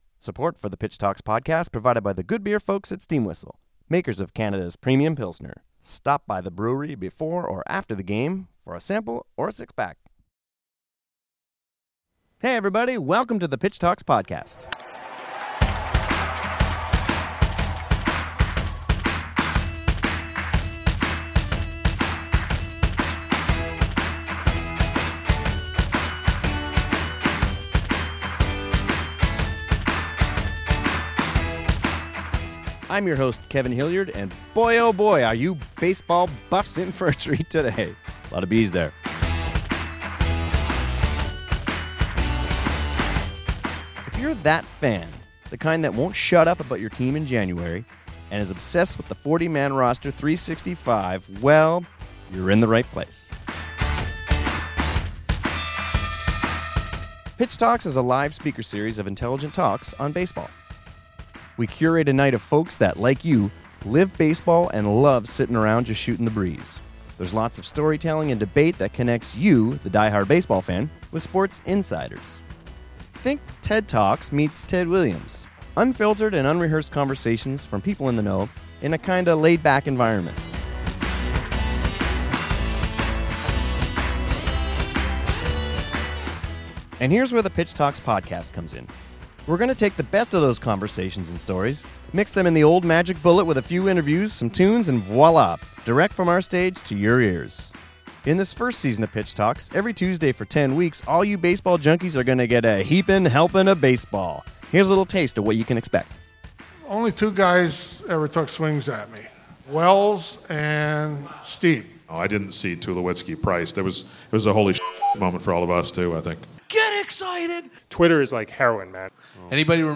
We're going to take the best of those live shows, mixed in with some interviews and new content, to continue to deliver high quality, intelligent baseball talk.